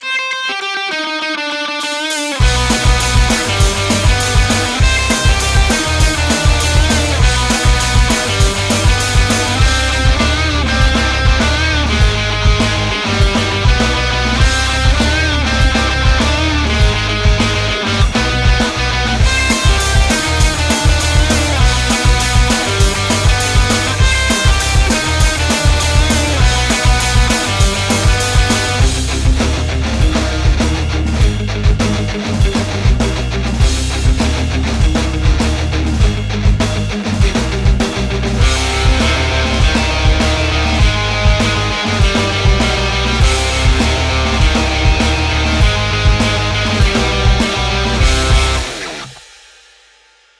Short rock demo: X50+Orange IR+Addictive Drums+Hardcore Bass